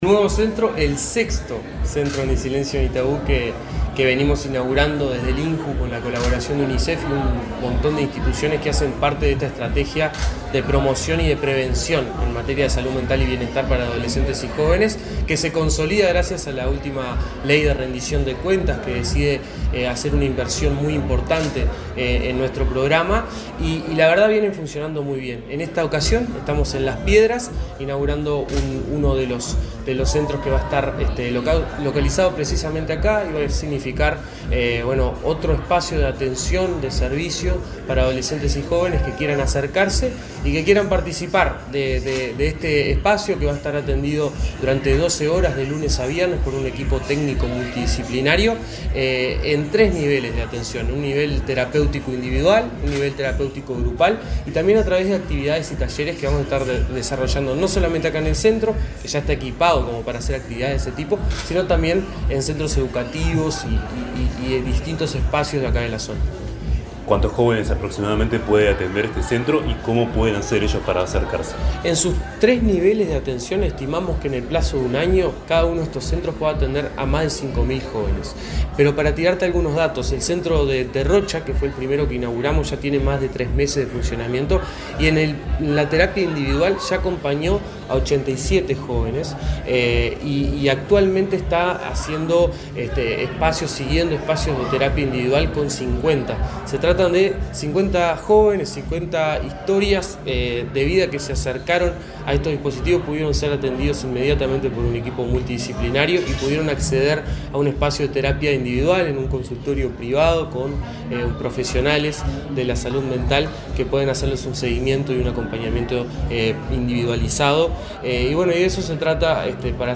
Entrevista al director del INJU, Aparicio Saravia